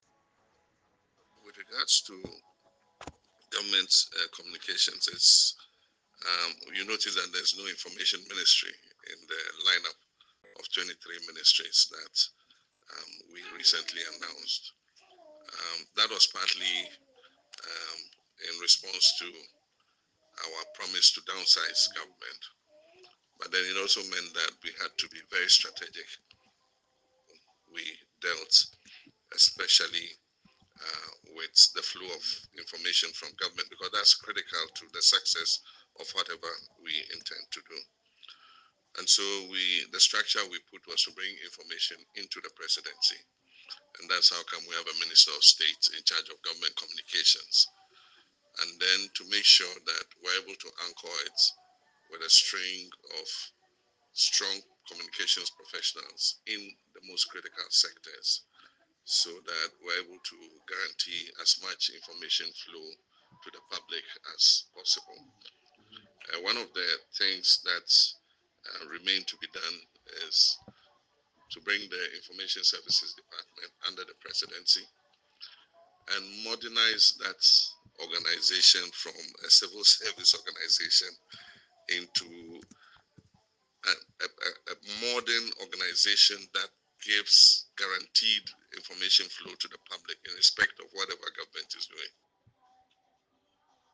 Addressing the media at a press engagement, President Mahama highlighted the importance of reducing redundancies while ensuring the seamless flow of government communication.